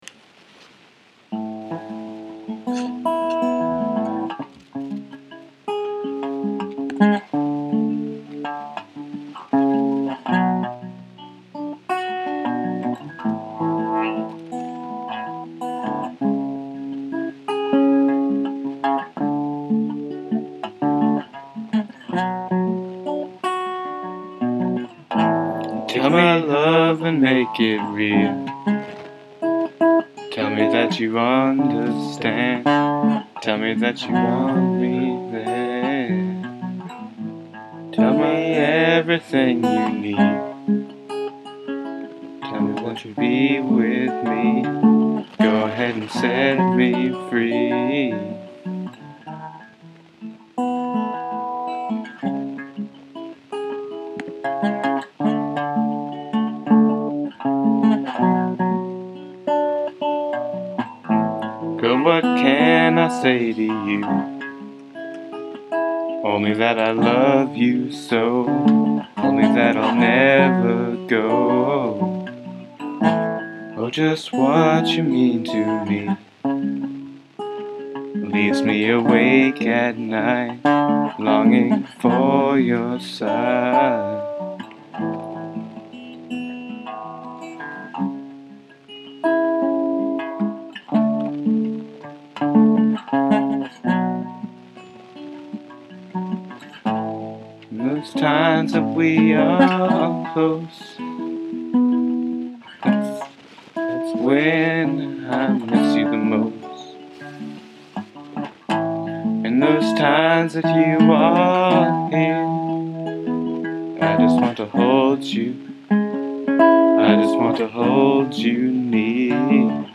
Just a sketch of those wistful Sunday afternoon blues for a chilly day in NYC, let me know what you think!